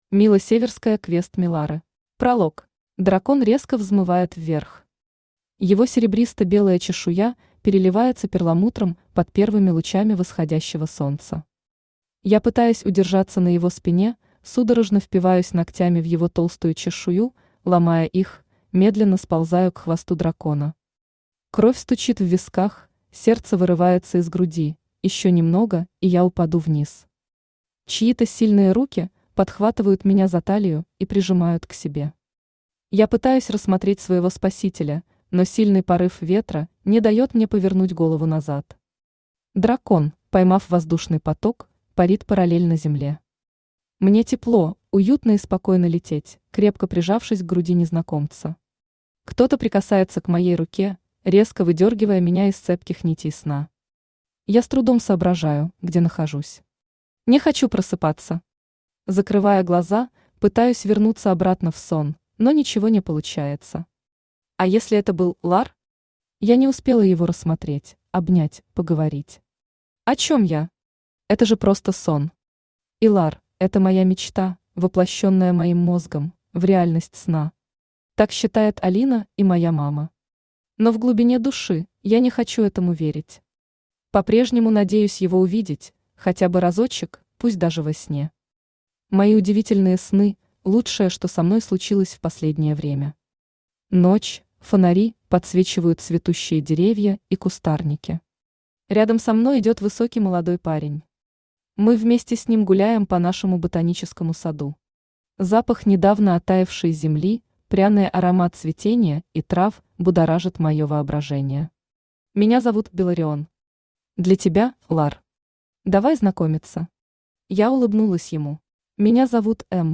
Aудиокнига Квест Милары Автор Мила Северская Читает аудиокнигу Авточтец ЛитРес. Прослушать и бесплатно скачать фрагмент аудиокниги